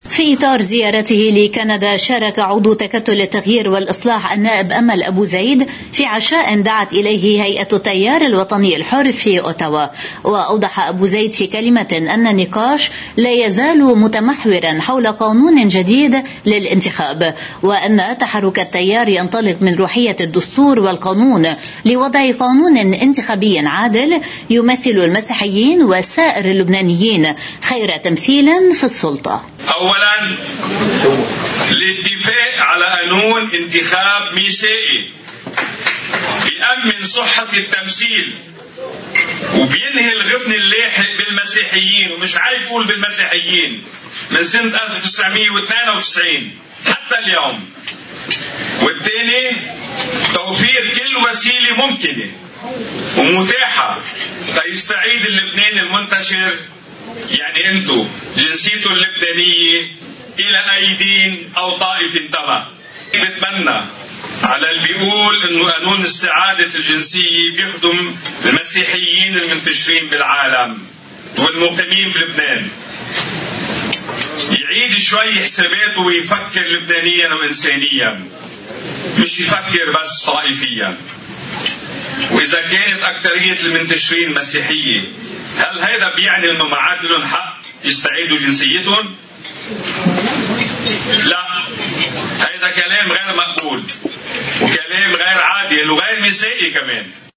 مقتطف من حديث عضو تكتّل التغيير والإصلاح، النائب أمل أبو زيد، في حفل عشاء هيئة التيار الوطني الحر في أوتاوا: